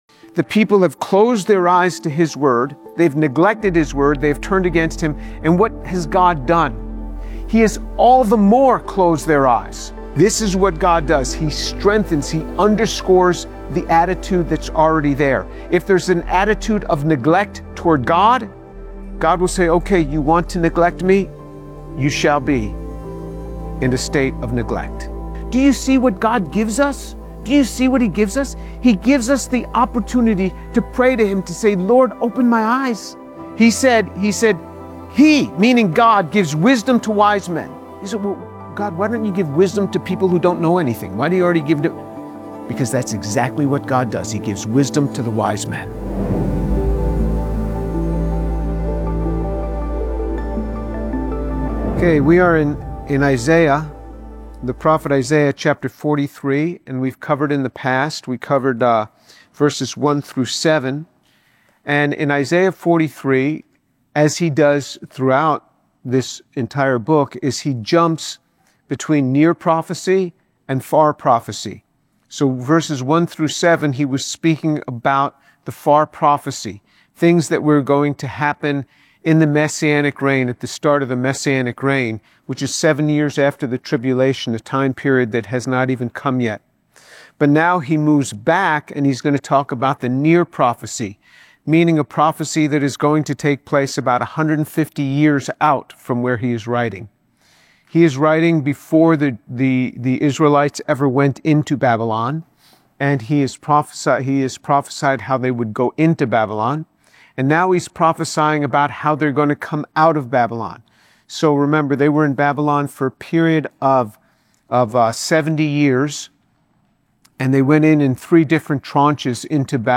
In this sermon, Dr. James Tour explains Isaiah 43’s message that God calls His people out of spiritual blindness and deafness, fulfilling prophecy in Christ and urging believers to seek God’s revelation rather than reject it. Dr. Tour contrasts Old Testament prophetic context with New Testament fulfillment, emphasizing that God opens the eyes of those who earnestly seek Him.